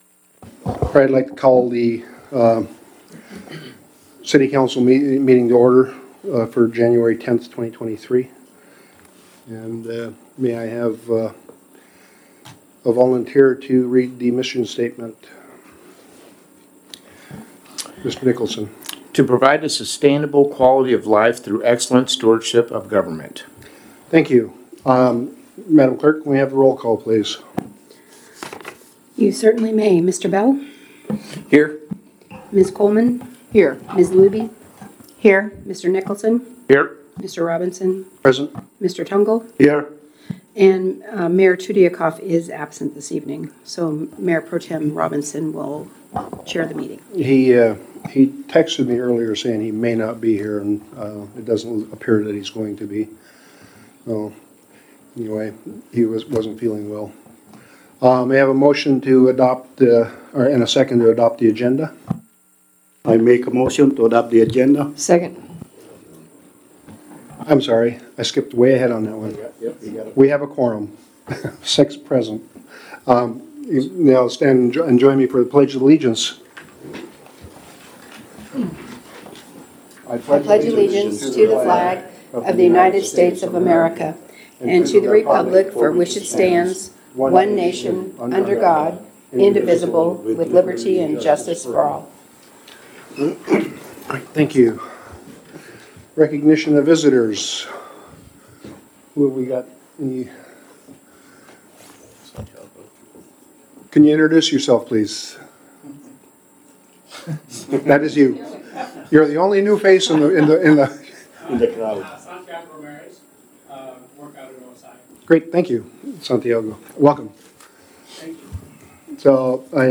City Council Meeting - January 10, 2023 | City of Unalaska - International Port of Dutch Harbor